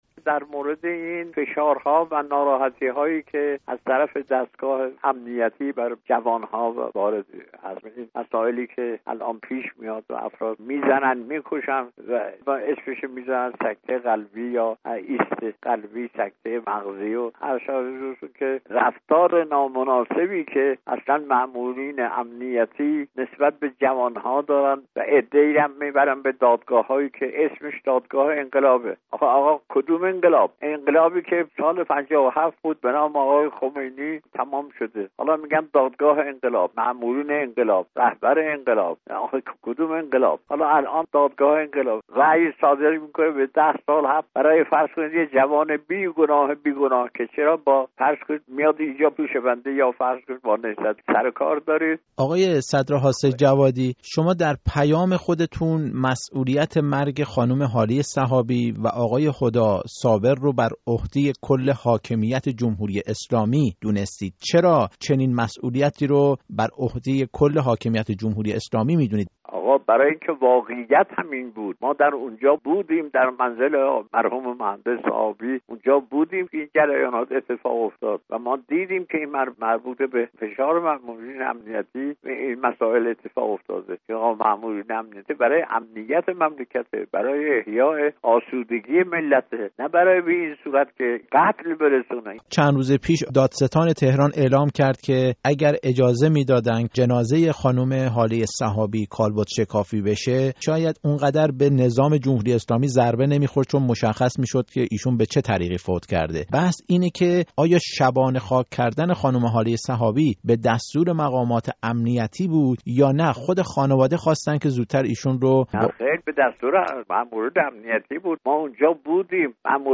گفت‌وگو با احمد صدر حاج سیدجوادی در مورد نامه‌اش به مراجع تقلید